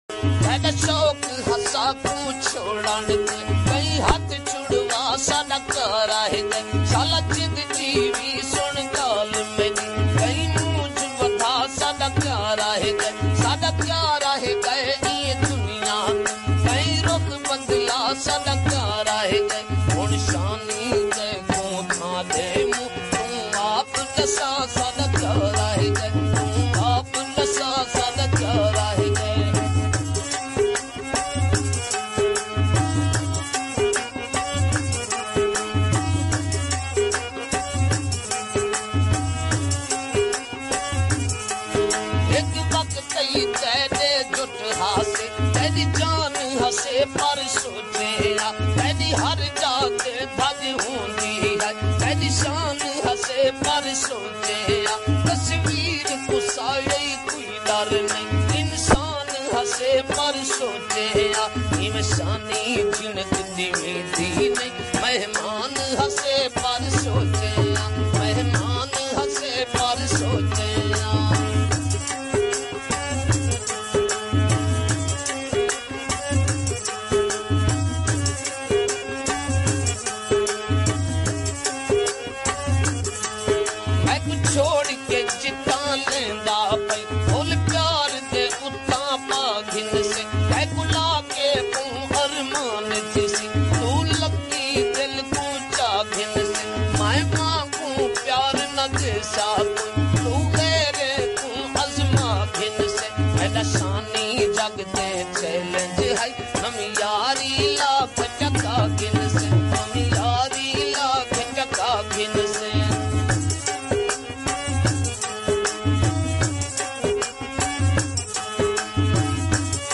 𝐏𝐚𝐤𝐢𝐬𝐭𝐚𝐧𝐢 𝐬𝐨𝐧𝐠# 𝐒𝐢𝐧𝐝𝐡𝐢 𝐒𝐨𝐧𝐠#𝐏𝐮𝐧𝐣𝐚𝐛𝐢 𝐬𝐨𝐧𝐠
𝐒𝐥𝐨𝐰𝐞𝐝 𝐚𝐧𝐝 𝐫𝐞𝐯𝐞𝐫𝐛